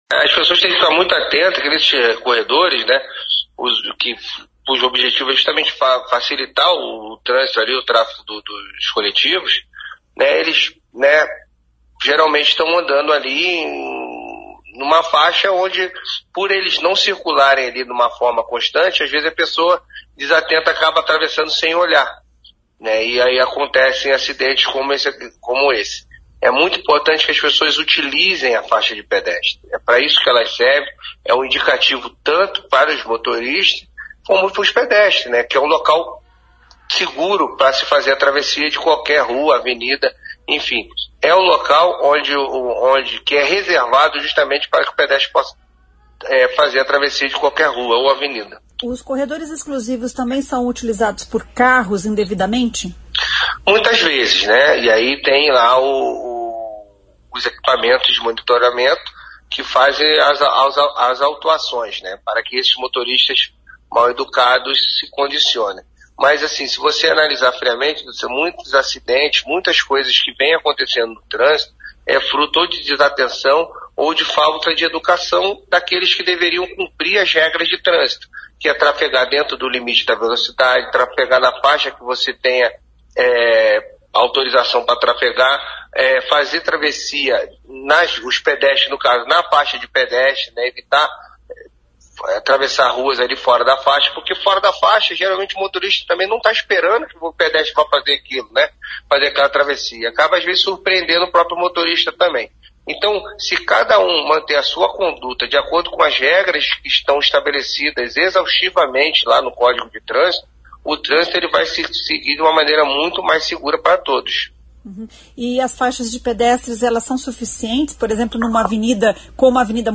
O secretário de Mobilidade Urbana, Luiz Alves, falou sobre o assunto: